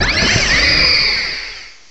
sovereignx/sound/direct_sound_samples/cries/tapu_lele.aif at 2f4dc1996ca5afdc9a8581b47a81b8aed510c3a8